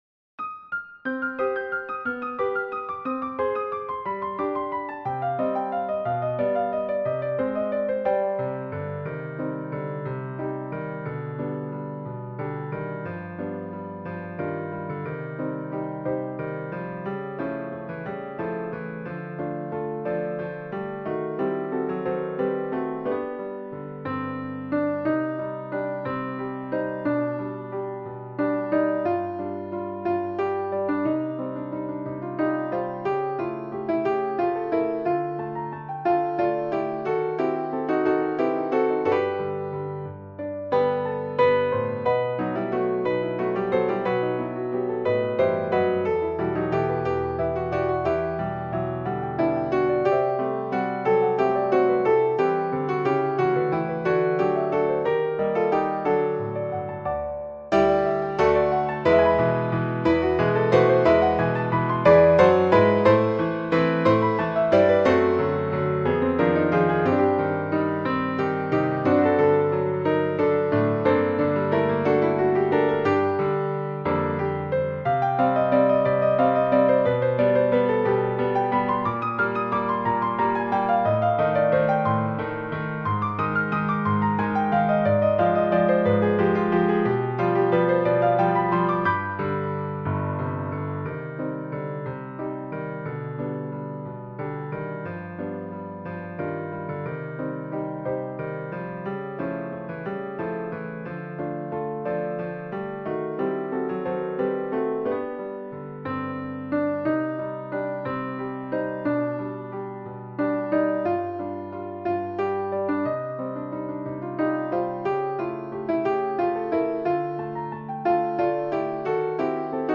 SAB mixed choir and piano